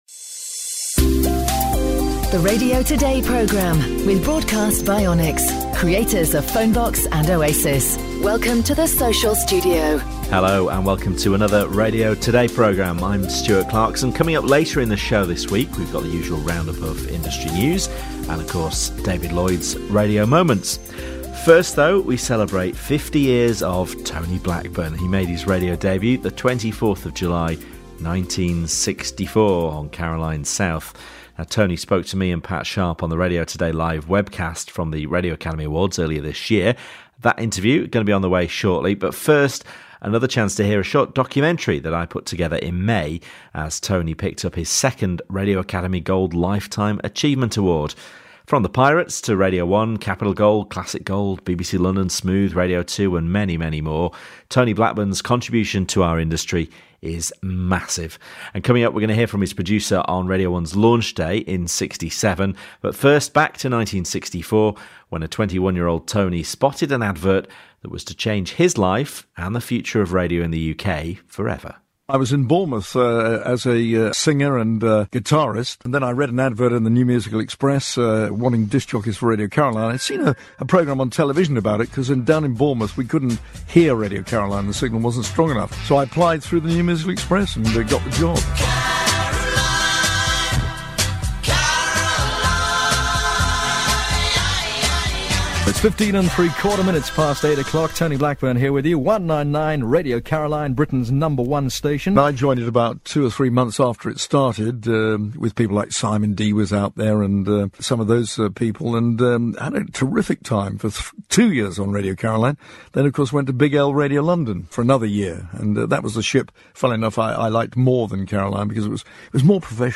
He chats to us and we bring you career highlights. Warning: contains jingles.